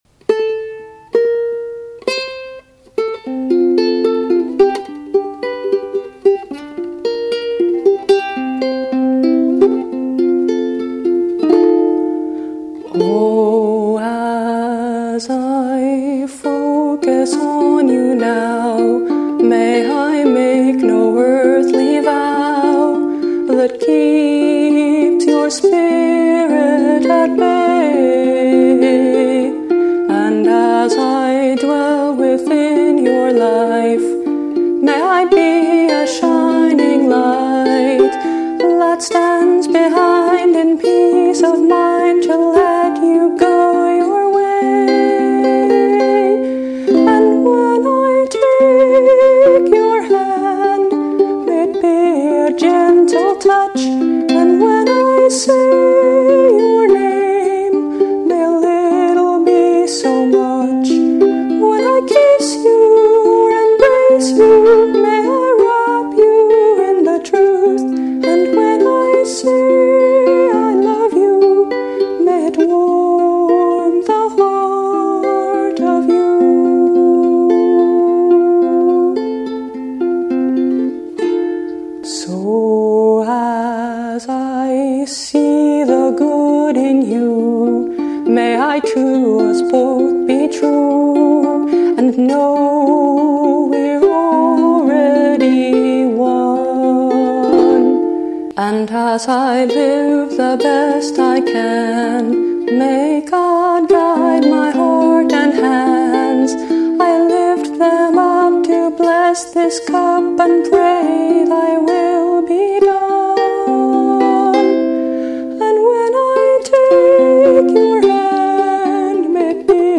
Instrument: Luna HTC-Koa ukulele (concert, reentrant)